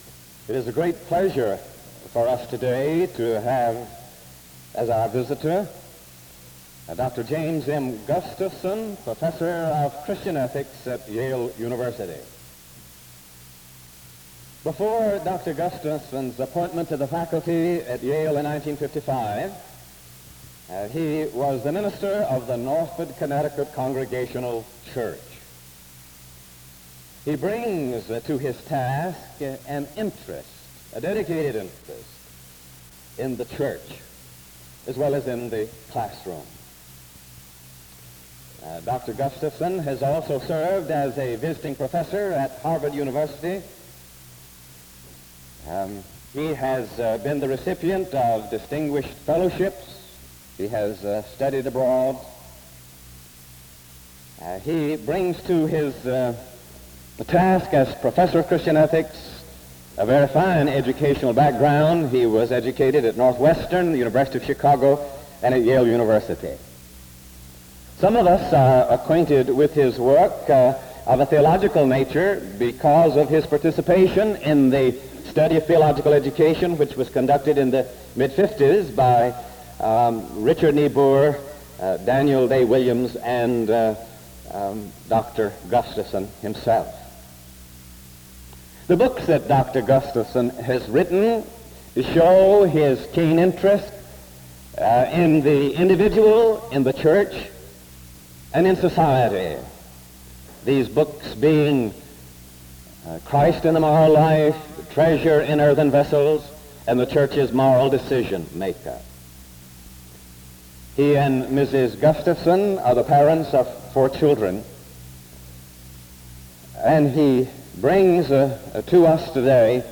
SEBTS Fall Lecture - James M. Gustafson September 21, 1971